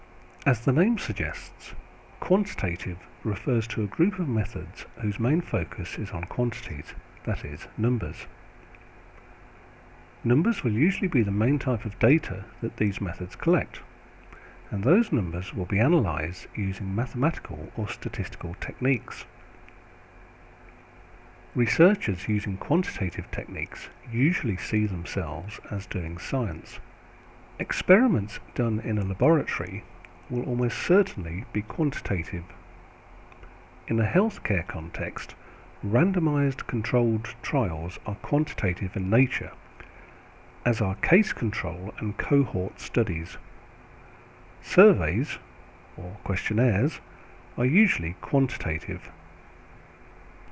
Narration audio (MP4) Narration audio (OGG) Contents Home Intro Quantitative Qualitative Complementary?